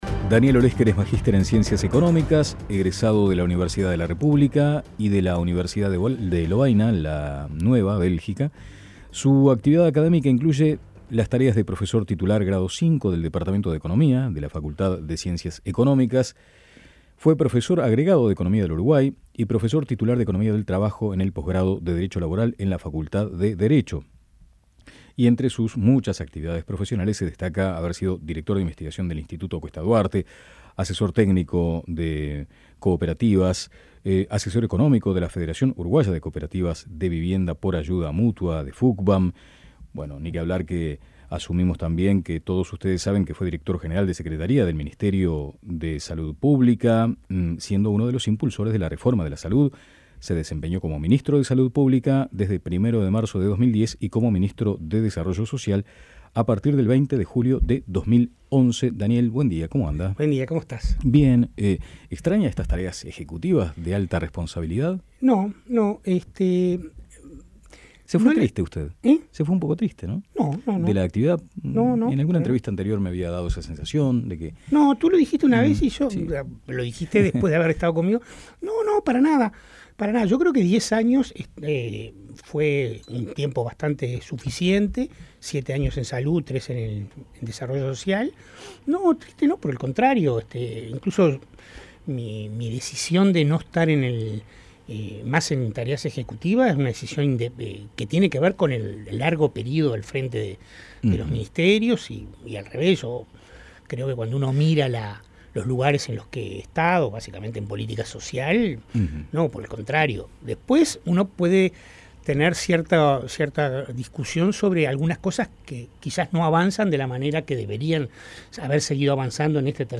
Entrevista a Daniel Olesker